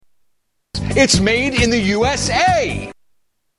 Tags: Media Doc Bottoms Aspray Doc Bottoms Aspray Ads Doc Bottoms Aspray Commercial Body Deodorant